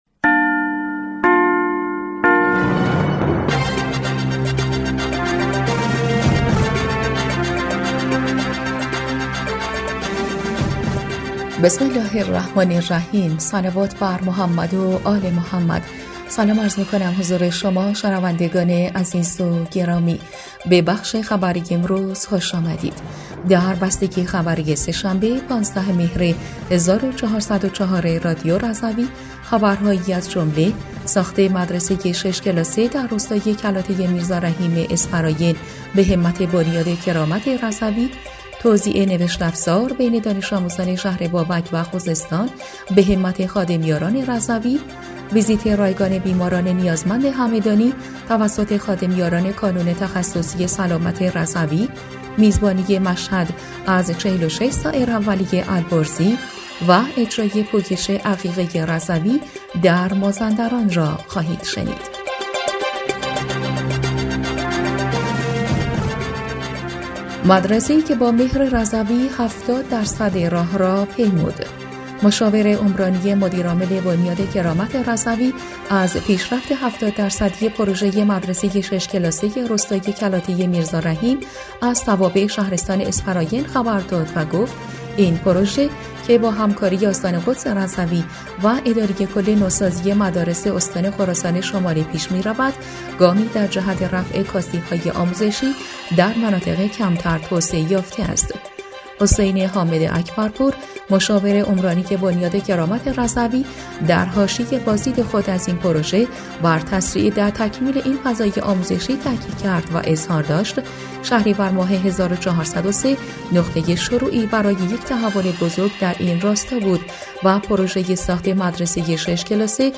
بسته خبری ۱۵ مهر ۱۴۰۴ رادیو رضوی؛